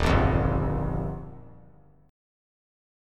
Listen to D#add9 strummed